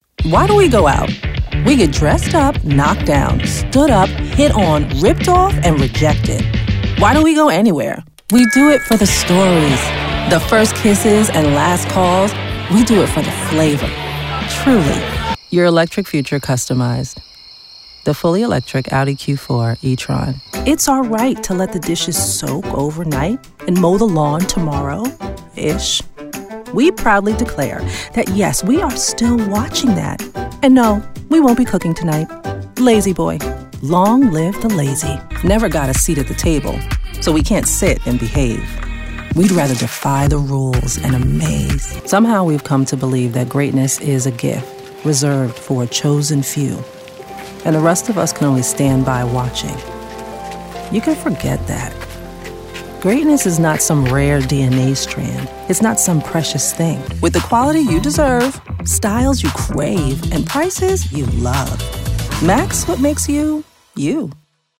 Voiceover
Commercial Demo